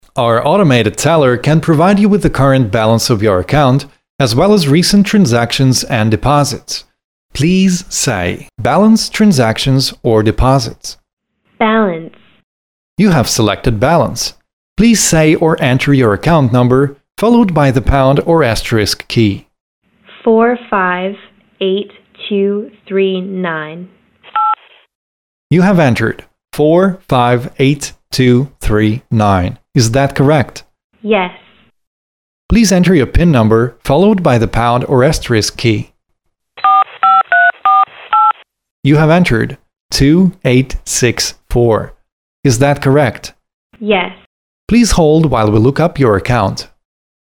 Запись голосового автоответчика для компании банковской сферы на английском языке.